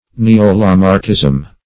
Neo-Lamarckism - definition of Neo-Lamarckism - synonyms, pronunciation, spelling from Free Dictionary
Neo-Lamarckism \Ne`o-La*marck"ism\, n. (Biol.)